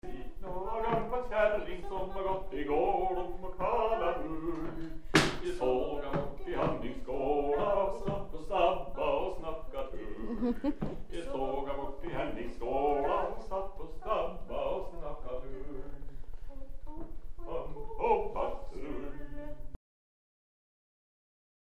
(song)